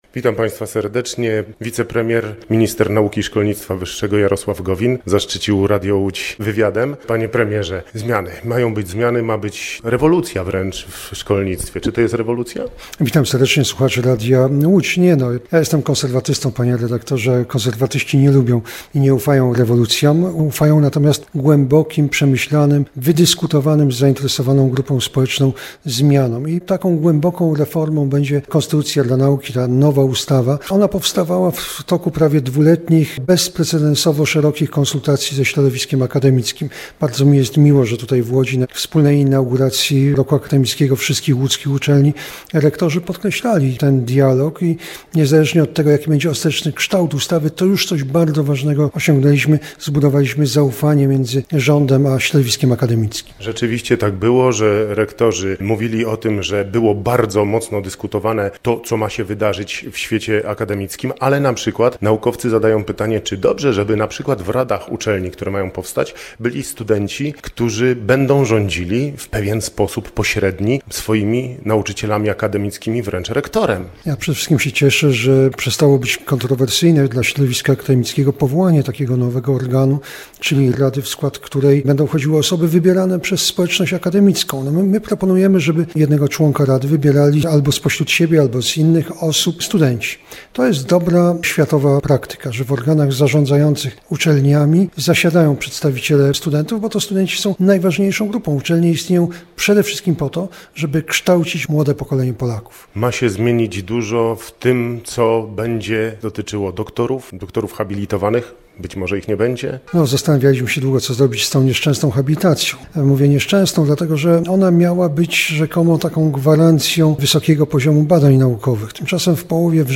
Posłuchaj rozmowy z ministrem Jarosławem Gowinem: Nazwa Plik Autor Jarosław Gowin o zmianach w szkolnictwie wyższym audio (m4a) audio (oga) Warto przeczytać Śmiertelne potrącenie w Sieradzu na DK 83 2 lipca 2025 To jest temat.